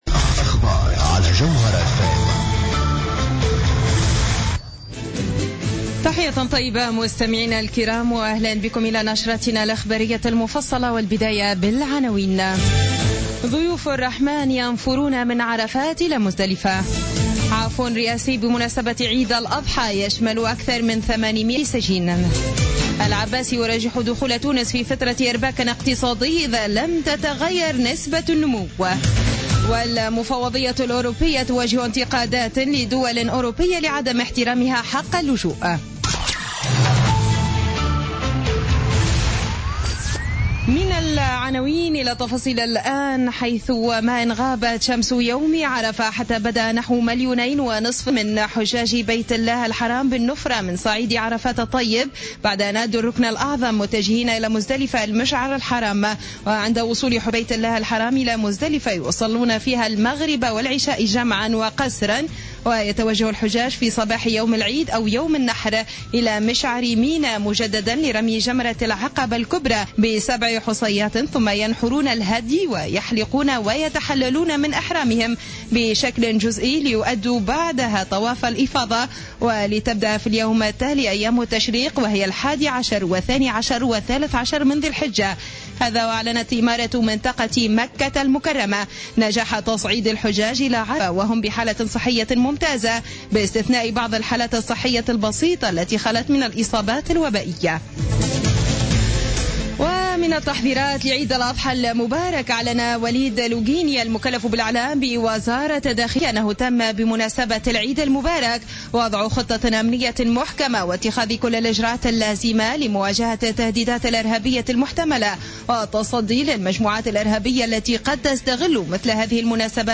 نشرة أخبار السابعة مساء ليوم الأربعاء 23 سبتمبر 2015